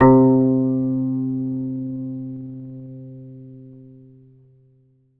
ZITHER C 1.wav